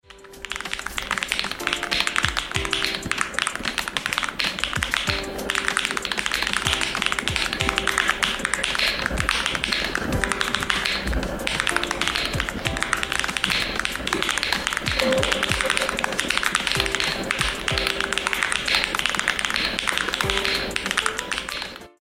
These switches from M1 V5 Rapid Trigger Gaming Keyboard come with extra pins for stabilizing, reversed polarity, and closed bottom for a better sound profile.
ASMR
M1-V5-TMR-ASMR.mp3